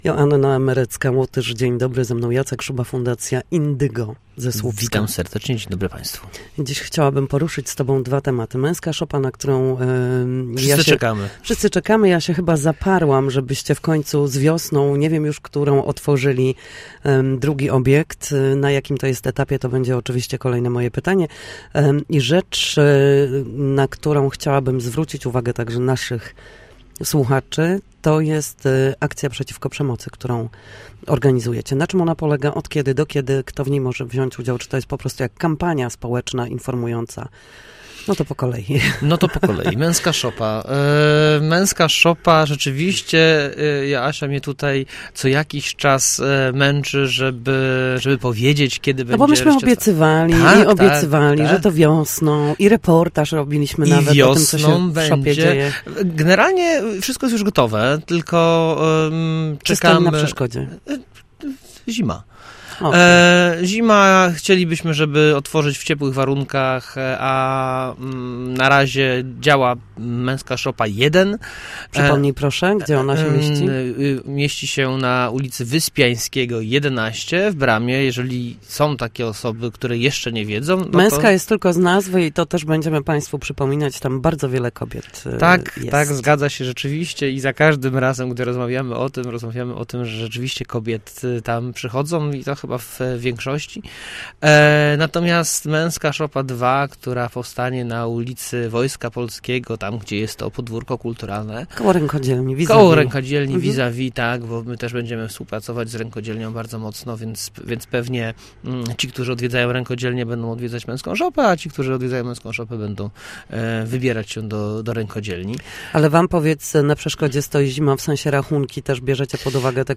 Przemoc ma różne oblicza. Gość Studia Słupsk mówi, jak jej zapobiegać i jak z nią walczyć